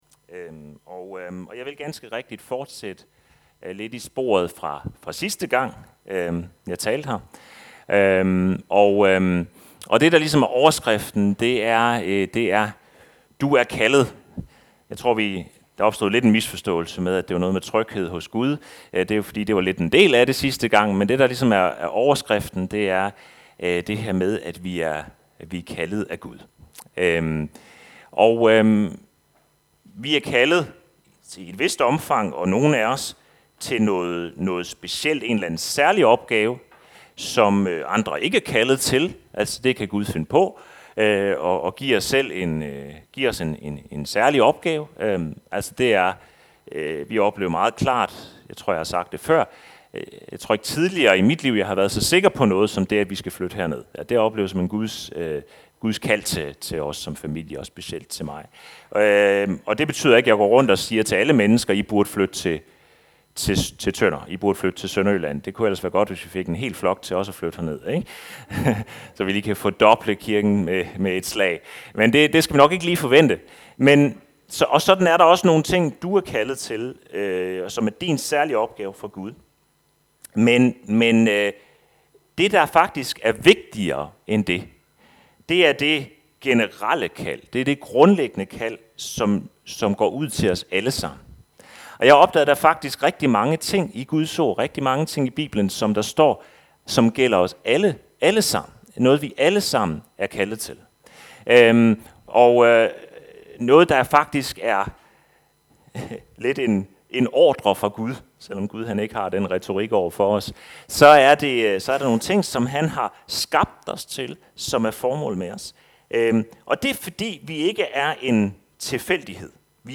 Passage: Galaterbrevet 5. 13-24 Service Type: Gudstjeneste